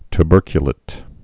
(t-bûrkyə-lĭt, ty-) also tu·ber·cu·lat·ed (-lātĭd)